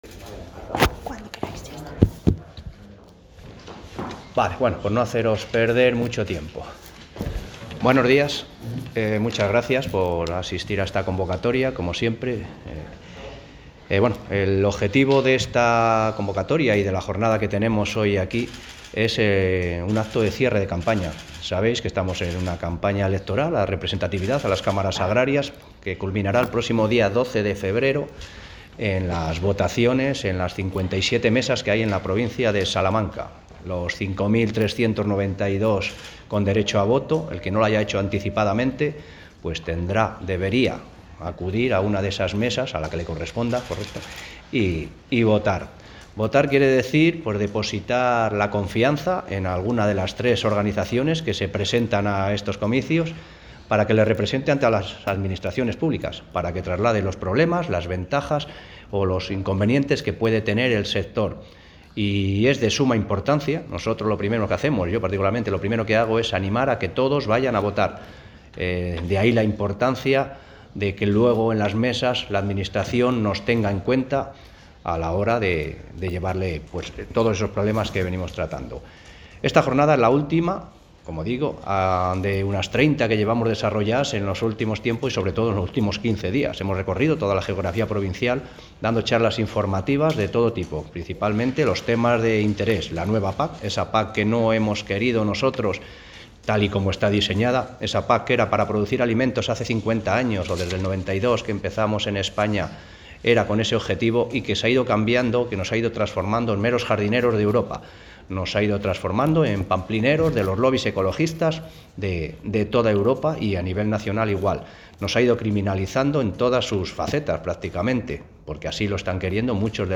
Escuche el audio de la rueda de prensa aquí: